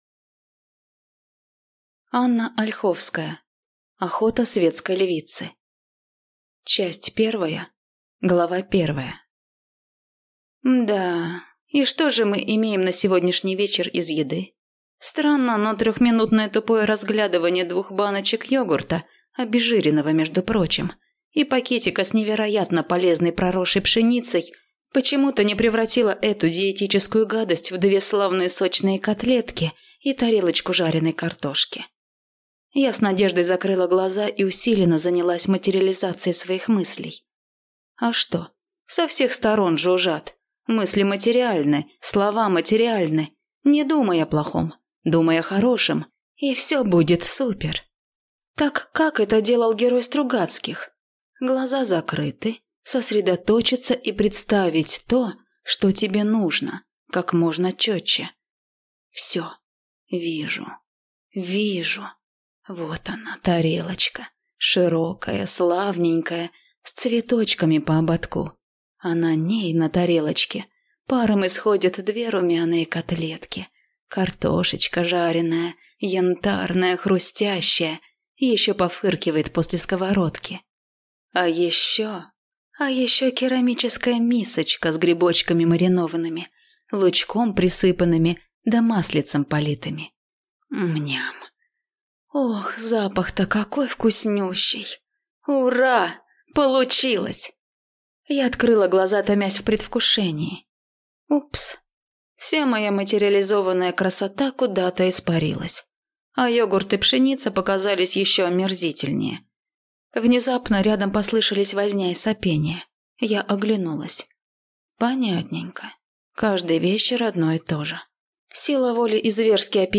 Аудиокнига Охота светской львицы | Библиотека аудиокниг
Прослушать и бесплатно скачать фрагмент аудиокниги